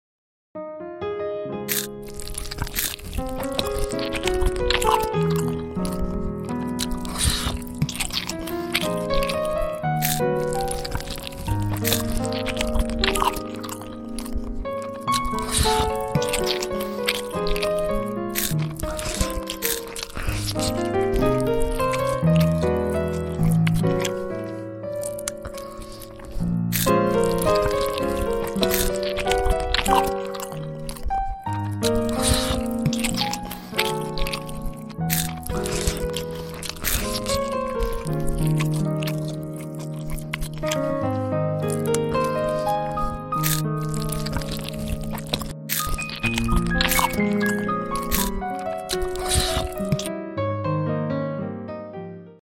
Always Share Asmr Mukbang sound effects free download
Asmr Eating Sounds